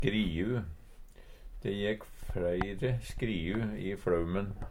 skriu - Numedalsmål (en-US)